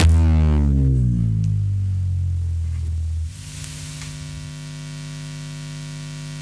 Distressed Double Bass.wav